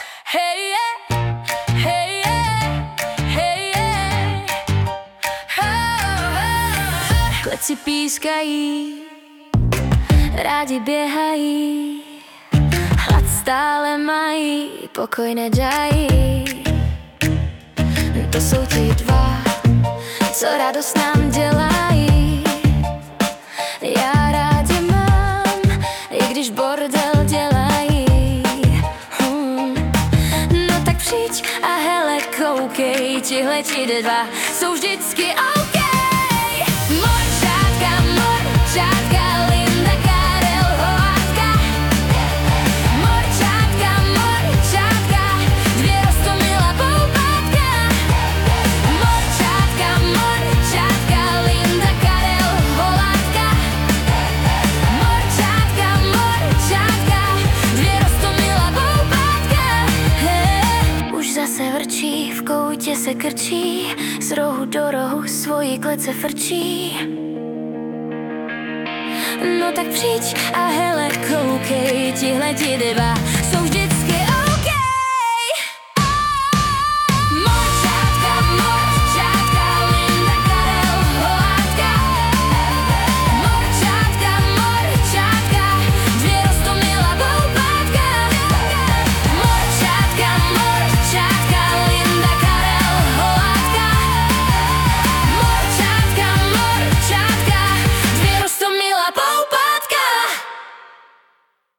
Tato písnička bude v budoucnu pravděpodobně přezpívaná opravdovým živým zpěvákem.